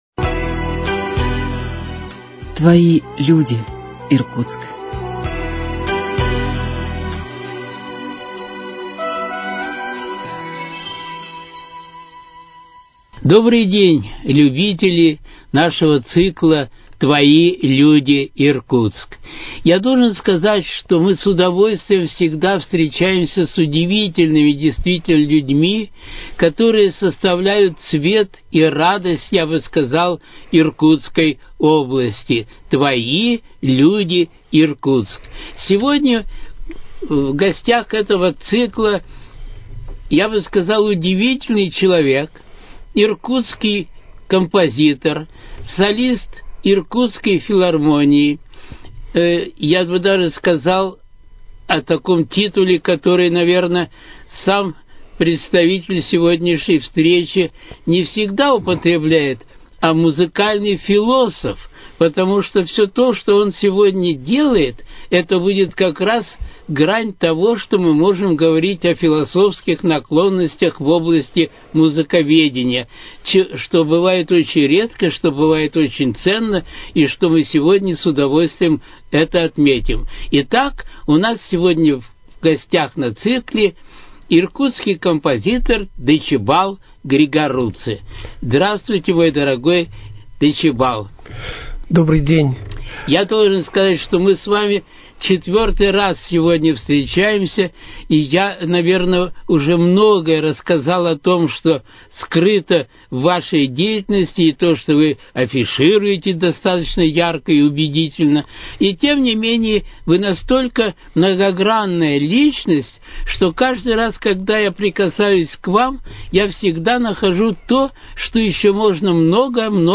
Твои люди, Иркутск: Беседа с композитором